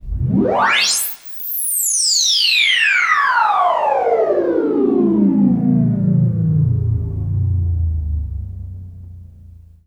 SPACESWEEP.wav